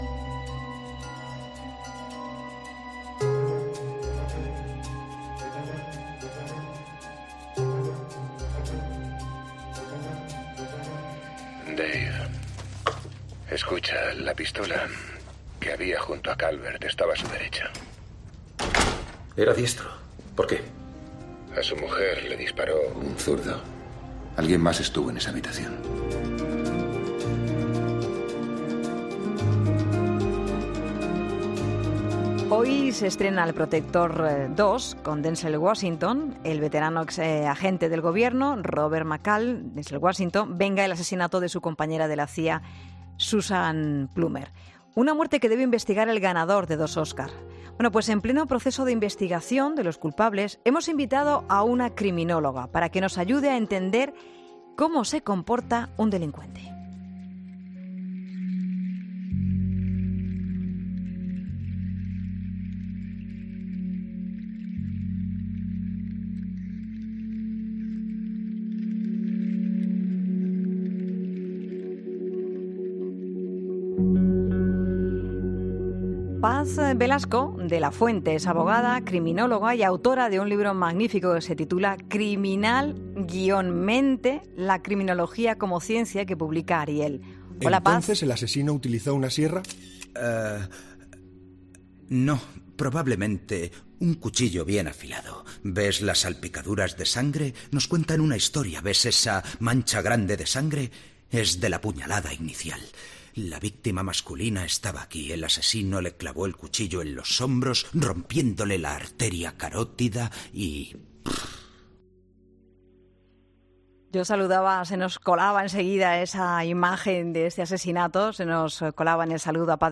Hablamos en 'La Tarde'